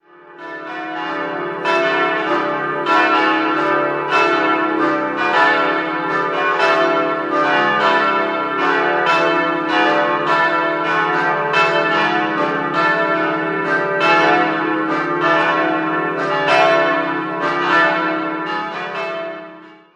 4-stimmiges ausgefülltes D-Dur-Geläute: d'-e'-fis'-a'
Die Glocken wurden 1947 von der Gießerei Schilling in Apolda gegossen.
bell
Die Glocken läuten an stark gekröpften Jochen, die Stimmungslinie des Geläuts ist hörbar unsauber.